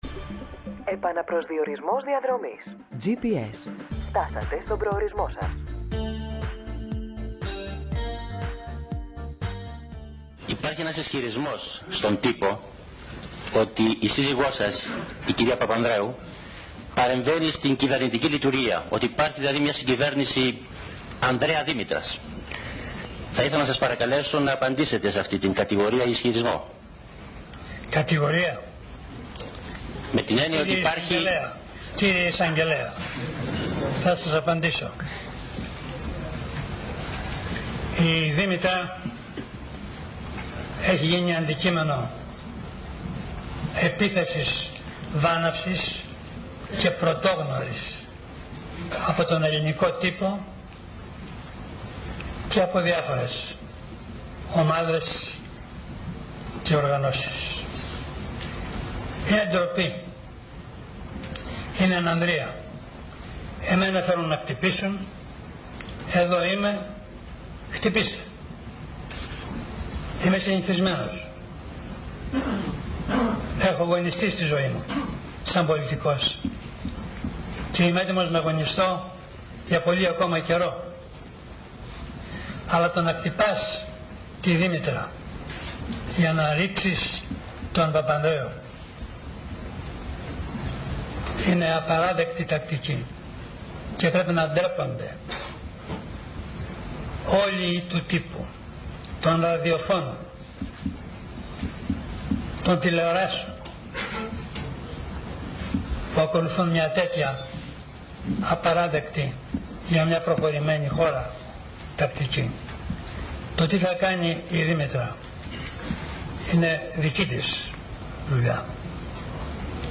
ο υπουργός Υγείας Άδωνις Γεωργιάδης,